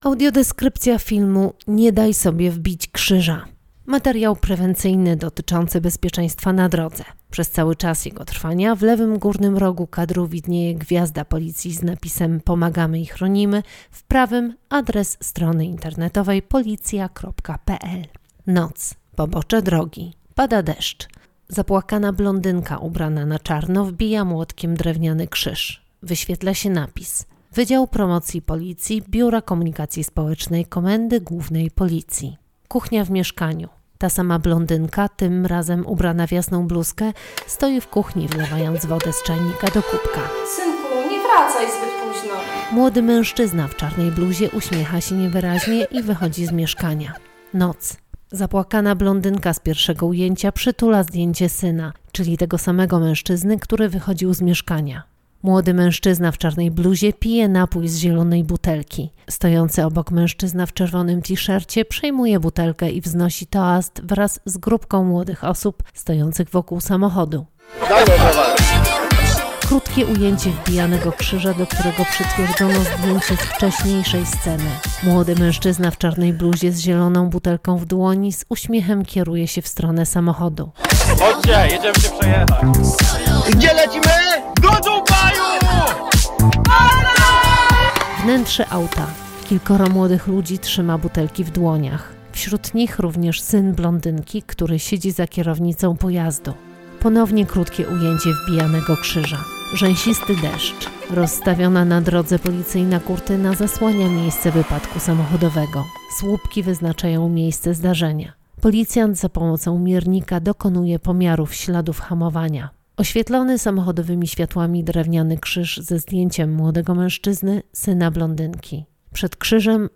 Nagranie audio Audiodeskrypcja do filmu: Nie daj sobie wbić krzyża!